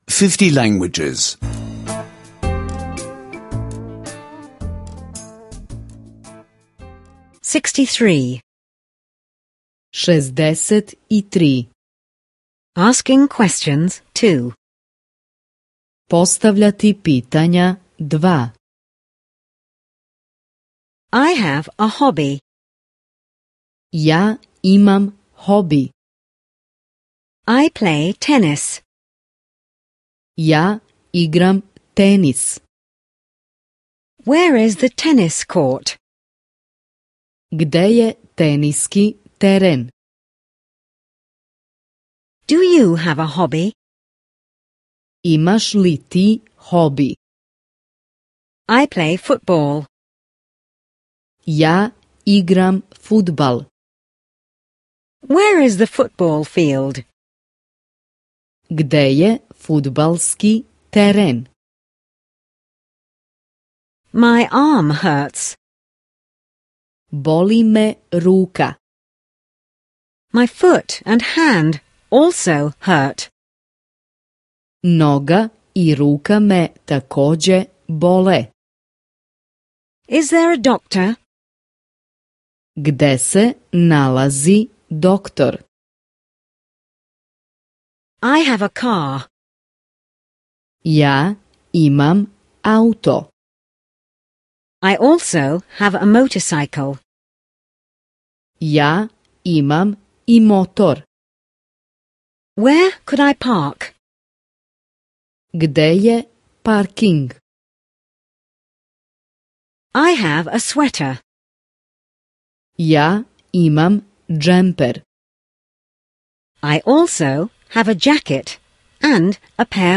Here you will find all parts of Serbian language audio lessons.